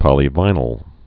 (pŏlē-vīnəl)